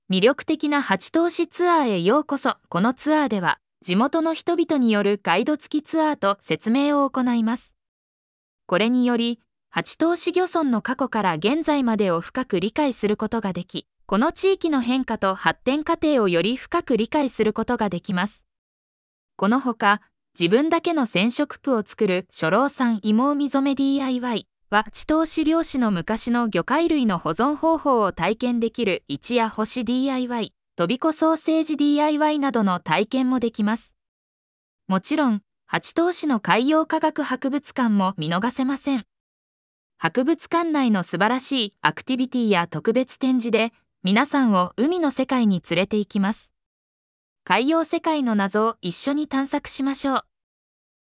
オーディオガイド